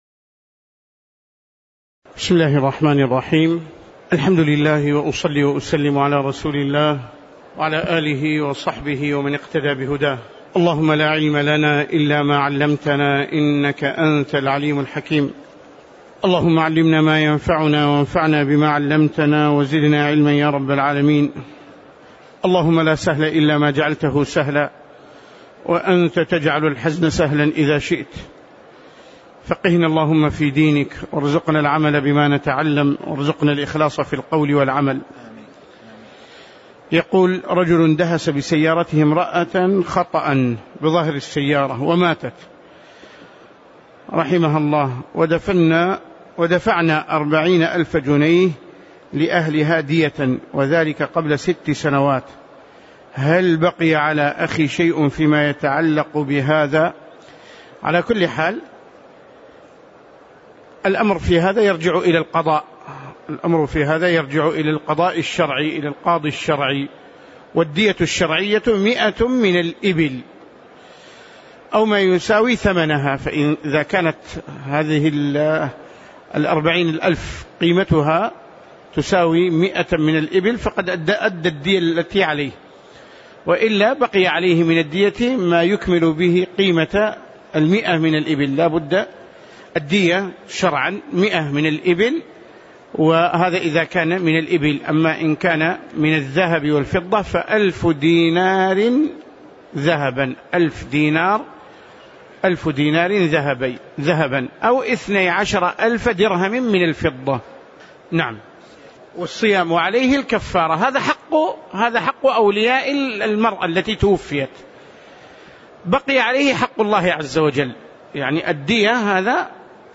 تاريخ النشر ٢٩ شعبان ١٤٣٧ هـ المكان: المسجد النبوي الشيخ